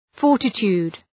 Προφορά
{‘fɔ:rtı,tu:d}